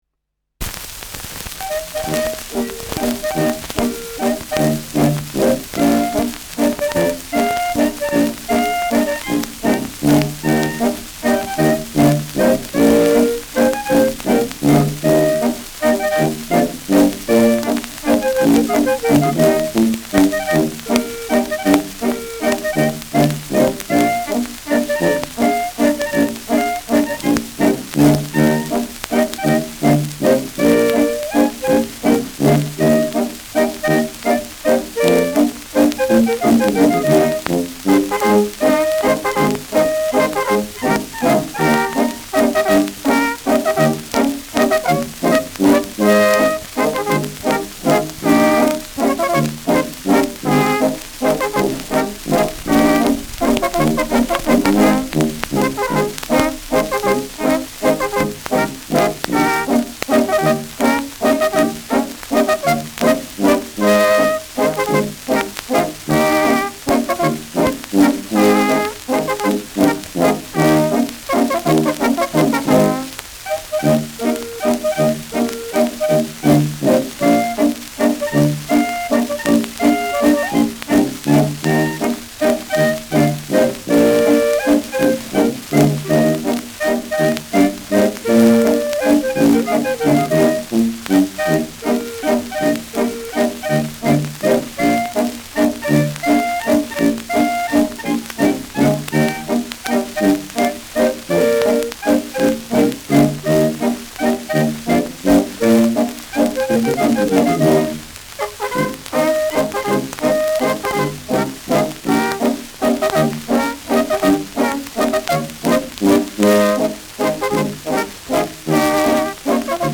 Schellackplatte
präsentes Rauschen : präsentes Knistern : abgespielt : leichtes Leiern : vereinzeltes Knacken
Kapelle Bosl, Falkenstein (Interpretation)